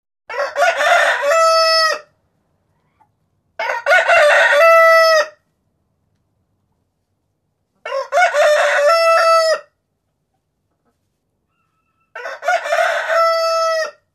» ПЕТУХ Размер: 229 Кб